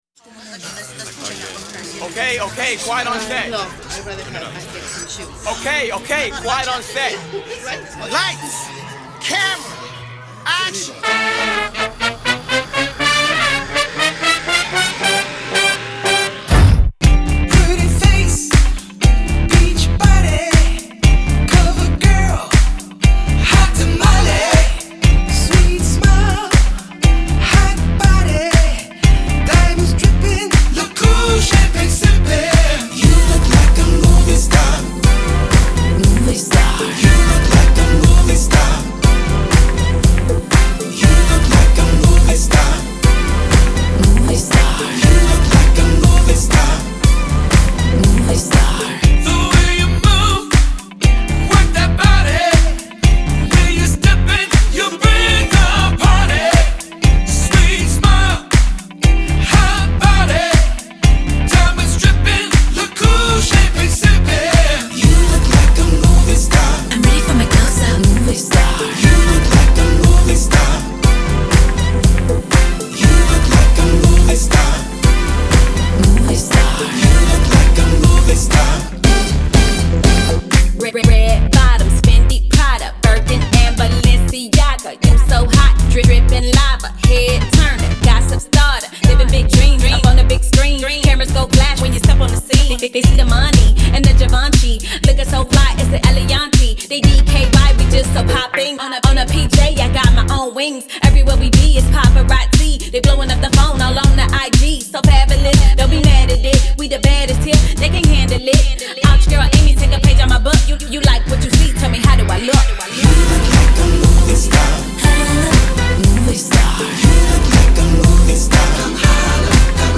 Genre : Funk